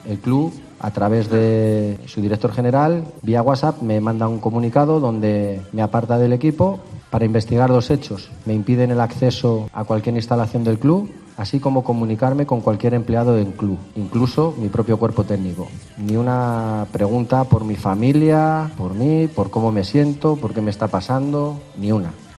El extrenador del Málaga ha dado una rueda de prensa para explicar la situación donde explica que lo más complicado fue contarle a sus hijos lo sucedido.